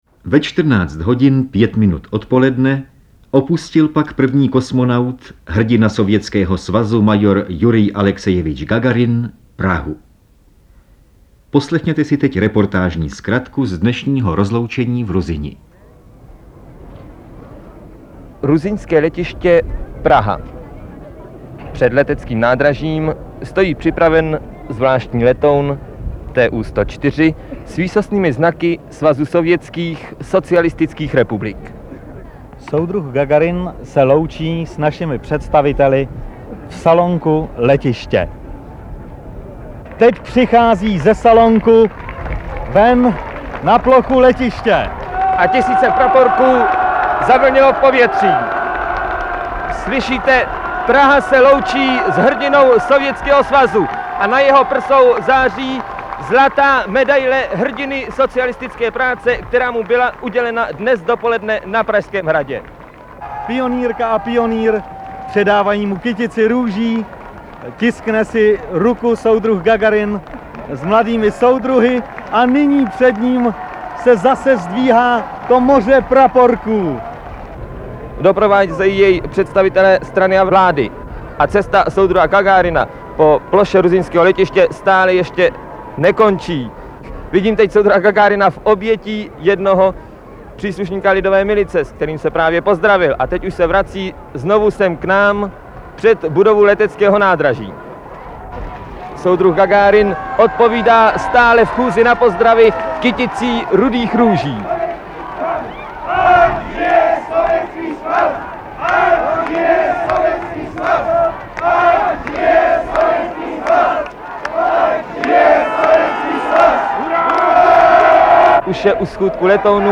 CD obsahuje celkem 53 historických reportáží, záznamů a zajímavostí z rozhlasového vysílání o dobývání vesmíru.
Audio kniha
Ukázka z knihy